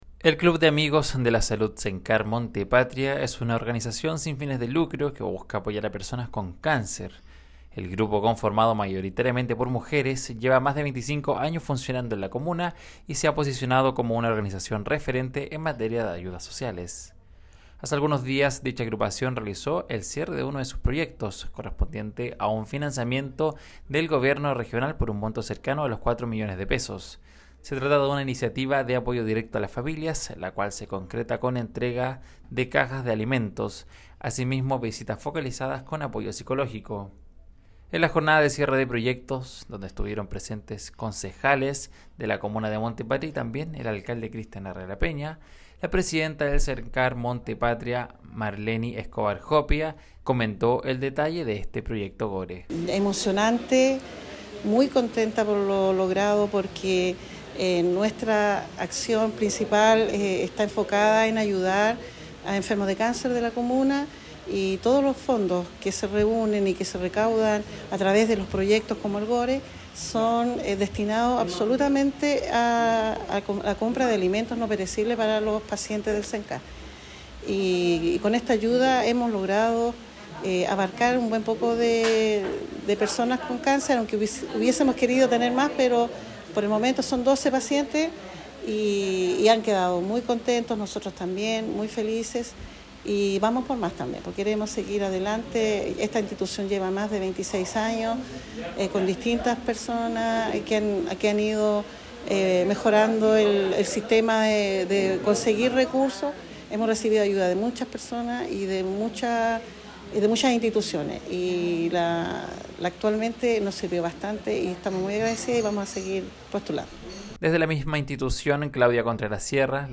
despacho_CENCAR-Monte-Patria.mp3